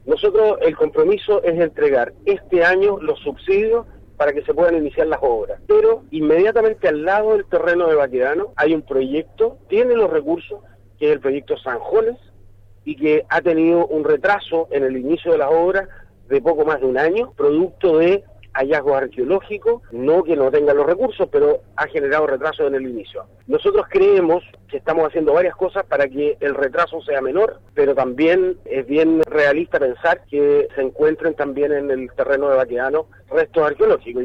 En conversación con Radio Sago, el Seremi de Vivienda y Urbanismo de la región de Los Lagos, Jorge Guevara, anunció que se destrabo el proceso judicial que esta en curso que buscaba evitar la construcción de viviendas en el Predio Baquedano de Osorno.